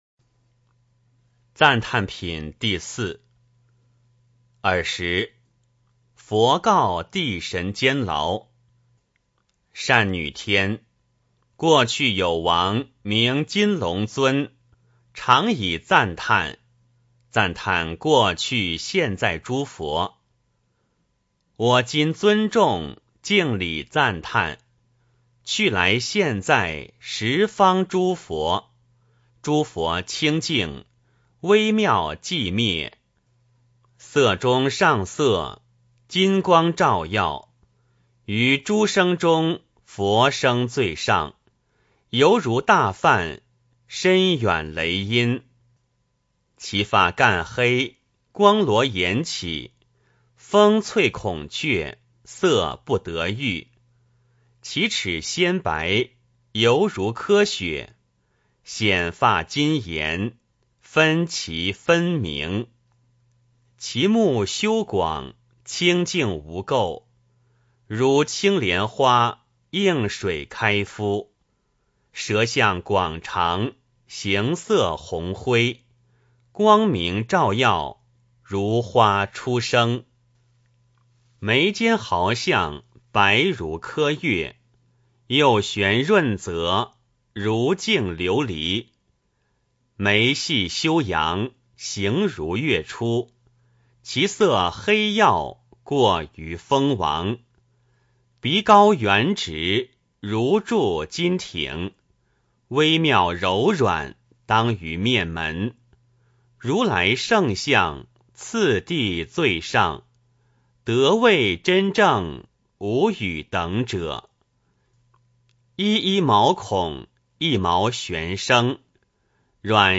金光明经04 诵经 金光明经04--未知 点我： 标签: 佛音 诵经 佛教音乐 返回列表 上一篇： 法句经-自己品 下一篇： 金光明经06 相关文章 黎明时分Daybreak--瑜伽静心曲 黎明时分Daybreak--瑜伽静心曲...